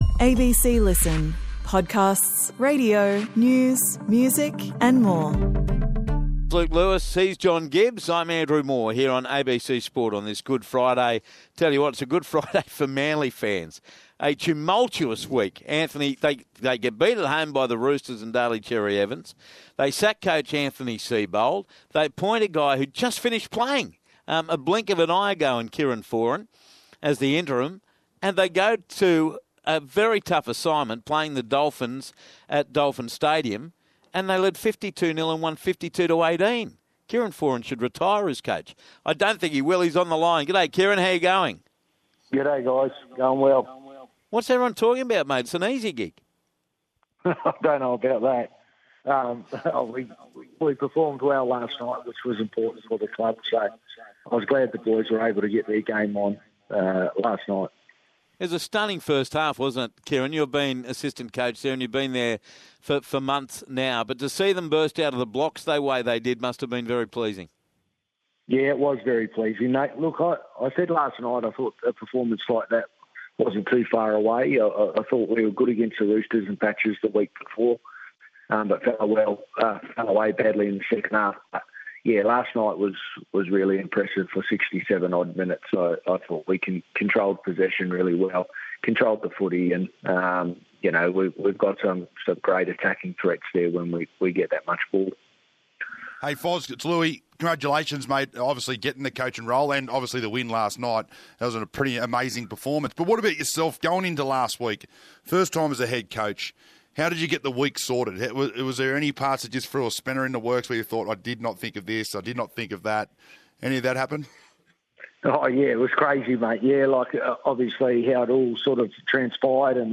And you can also listen to the wrap up of South Sydney's impressive win over the Bulldogs including all the post match interviews.